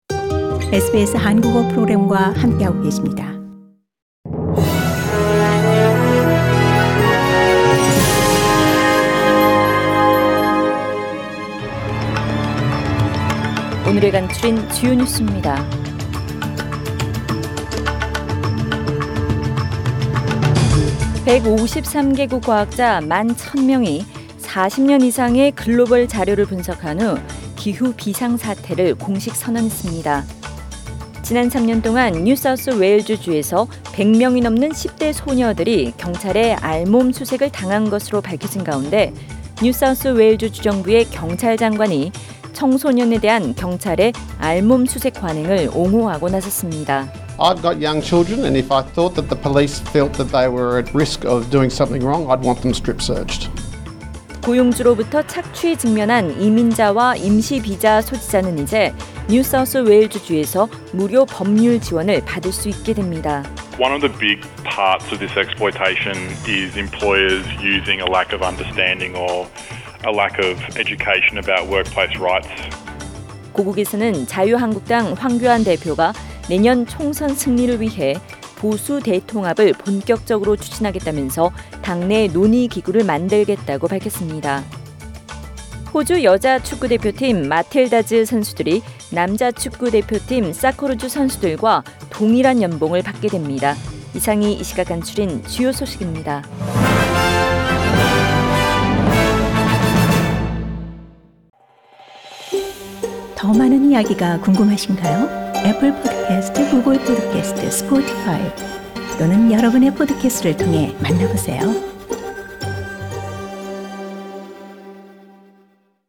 SBS Korean News Source: SBS Korean